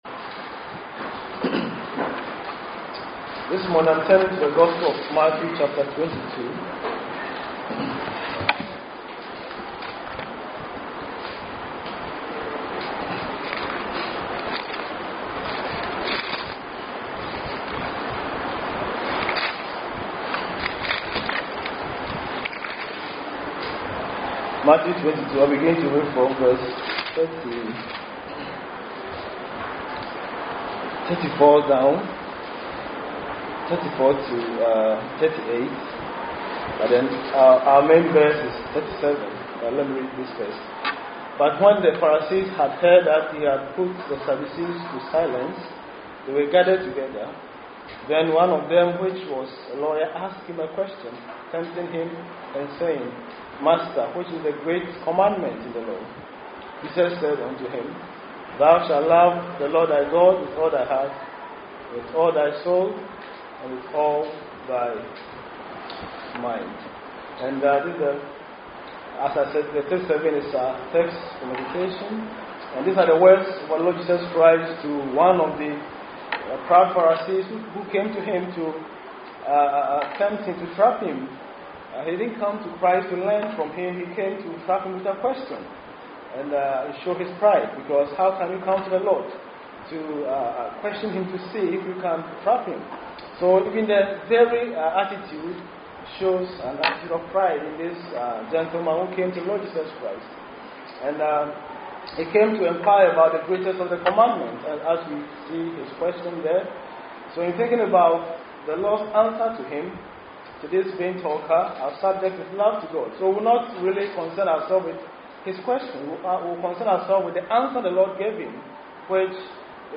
Evangelistic Sermons – Sovereign Grace Reformed Baptist Church